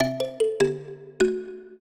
mbira